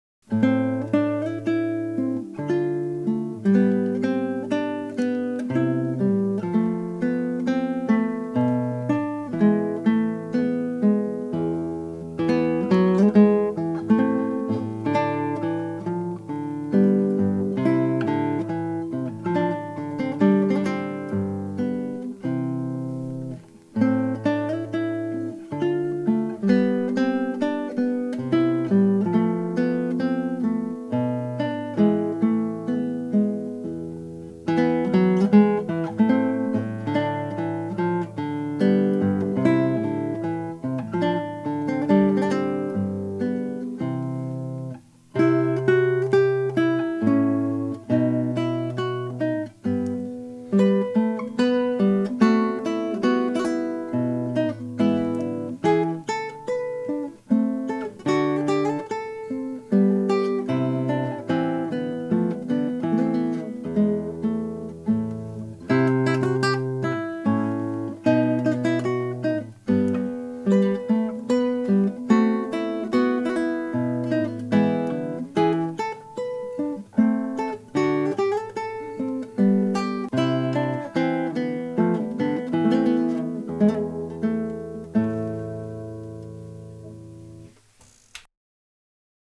Melda MAutoalign auf beide Monospuren, beide hart links und rechts gepannt: Dein Browser kann diesen Sound nicht abspielen.